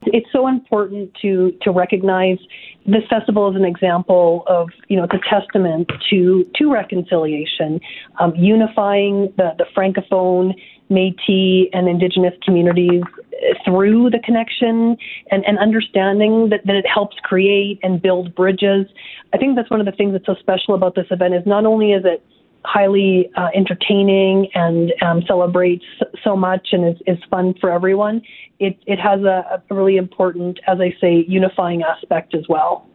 Tanya Fir, Minister of Arts, Culture, and status of women spoke with Windspeaker Radio Network about the funding contribution towards the Flying Canoe event.